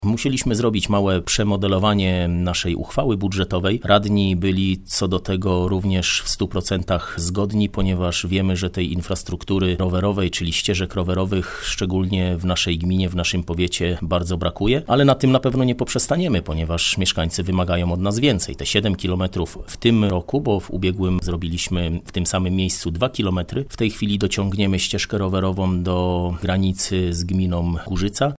– Gminę będzie to kosztować 2,5 mln złotych, a droga dla cyklistów powstanie na koronie wału przeciwpowodziowego – informuje burmistrz Słubic Mariusz Olejniczak.